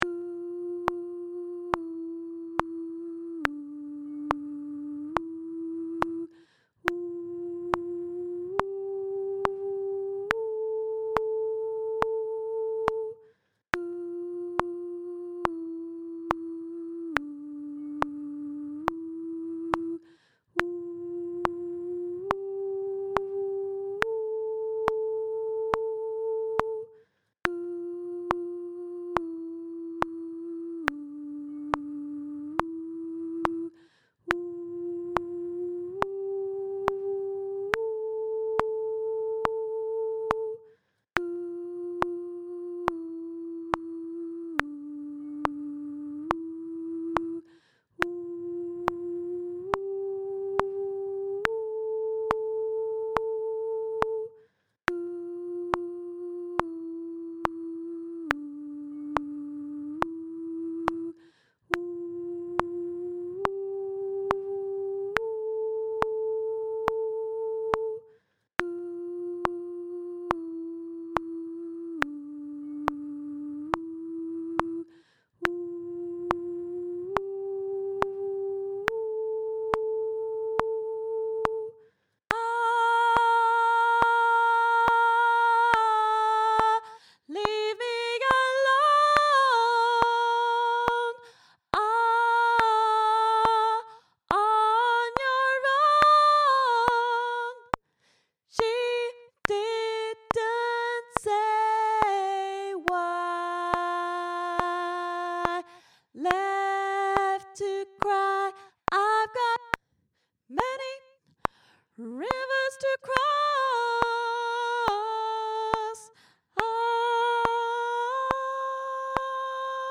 Many-Rivers-Soprano - Three Valleys Gospel Choir
Many-Rivers-Soprano.mp3